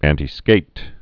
(ăntē-skāt, ăntī-) or an·ti·skat·ing (-skātĭng)